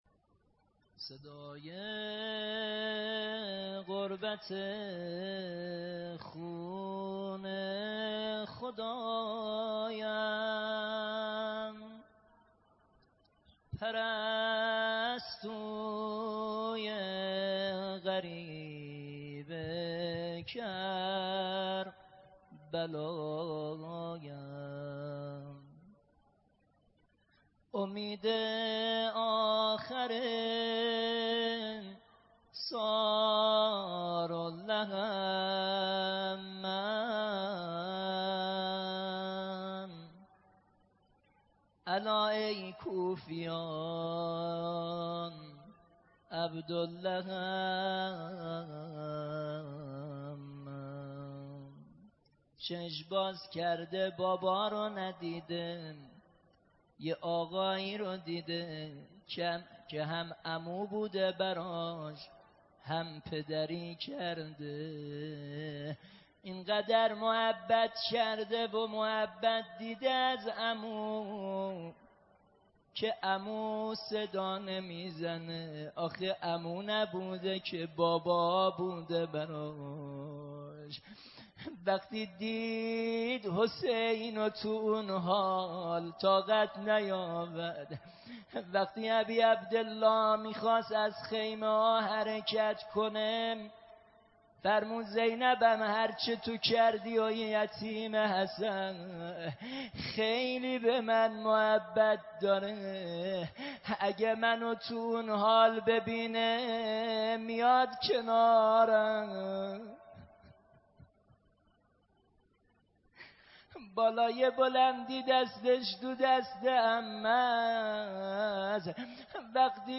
مصلی خاتم الانبیاء احمدآباد